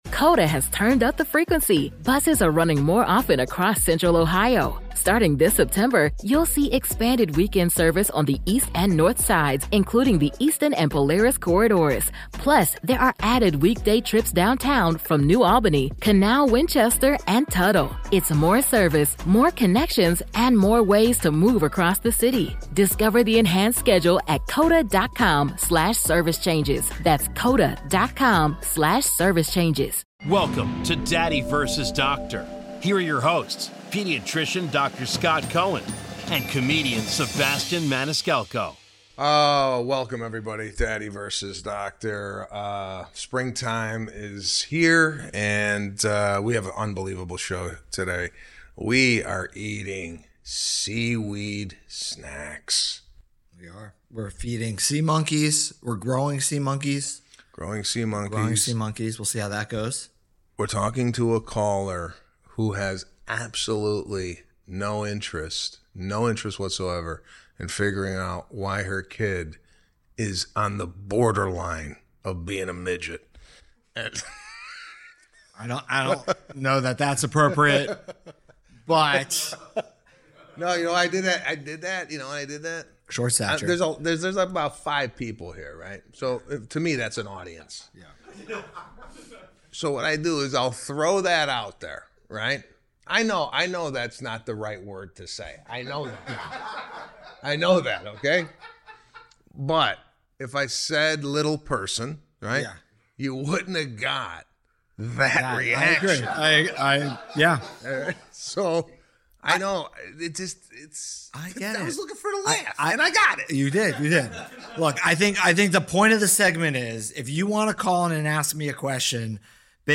The boys are mimicking each other, failing sea monkeys, talking TV conspiracies and chowing down sea snacks while all the guest callers are headed to the Maniscalco family reunion on this week's Daddy vs. Doctor!